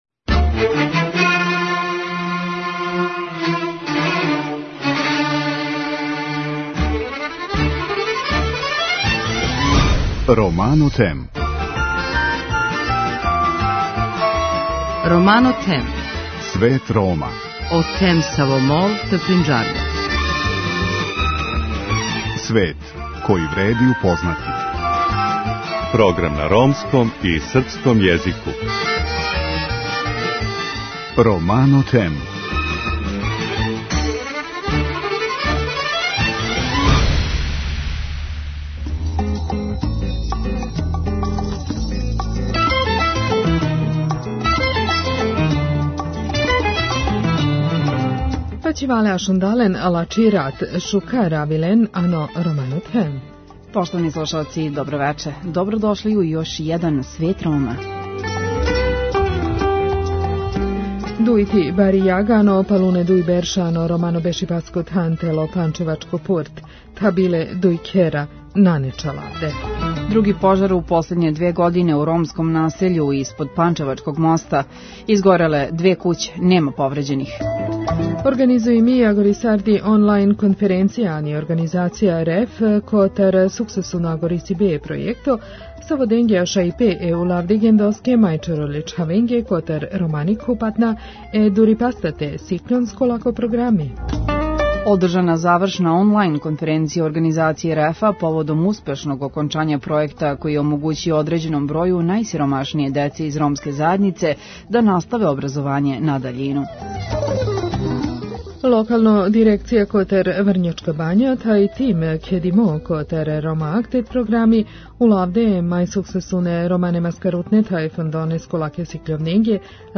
Vesti na romskom jeziku